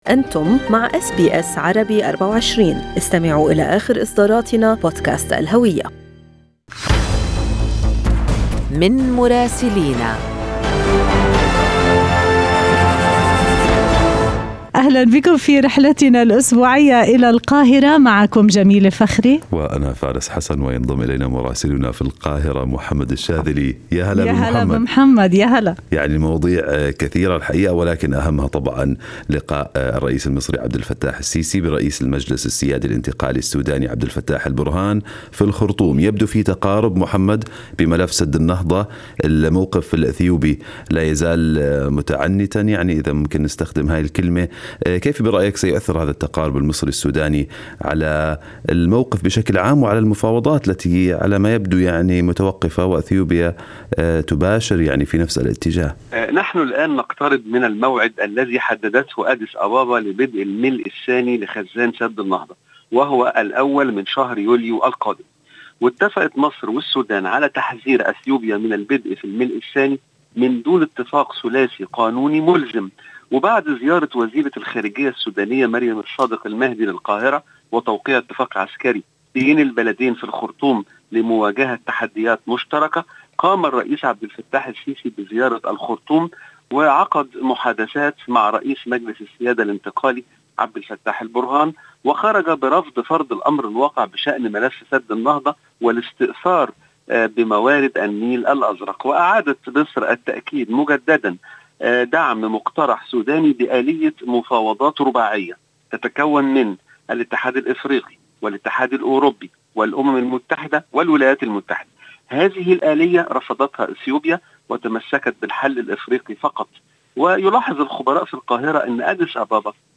من مراسلينا: أخبار مصر في أسبوع 10/3/2021